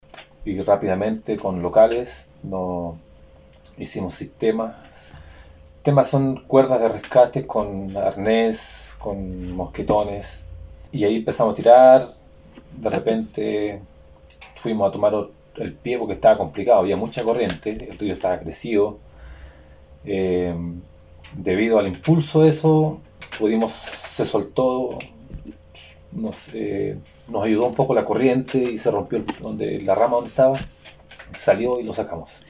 31-EL-ESPOLON-TESTIMONIO-2.mp3